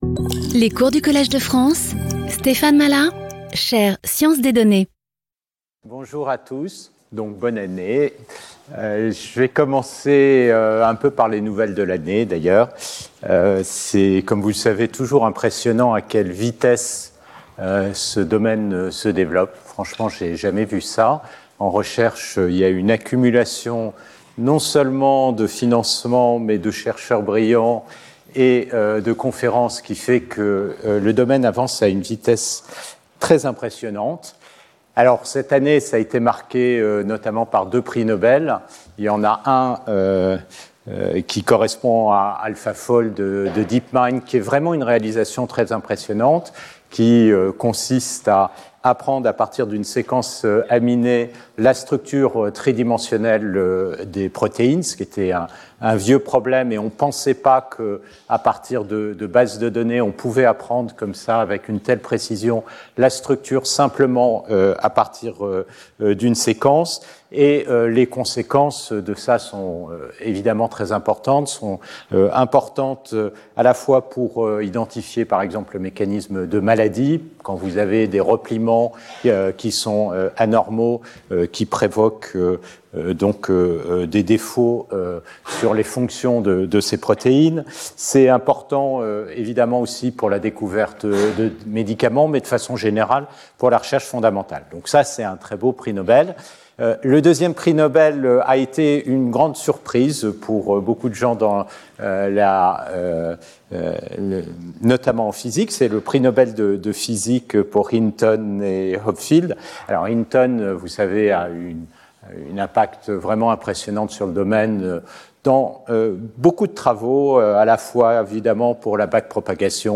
Speaker(s) Stéphane Mallat Professor at the Collège de France
Lecture